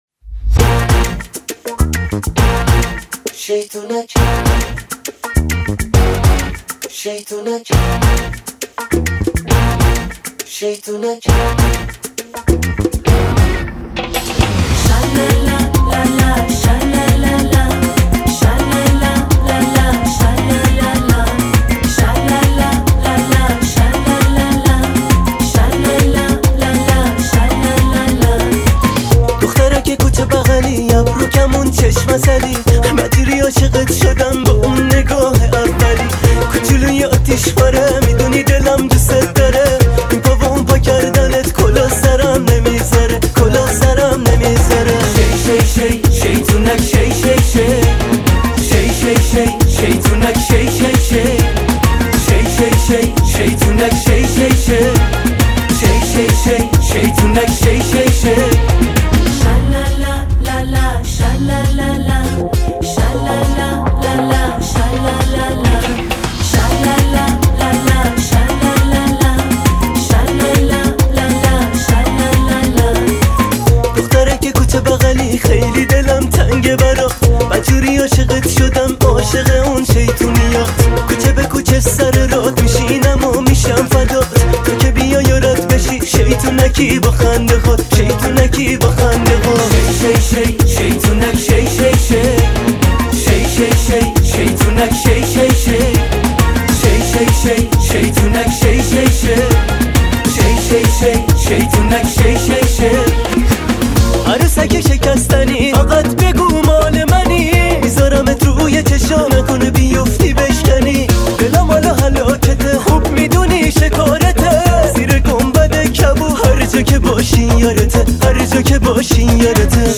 آهنگ جدید زیبا و احساسی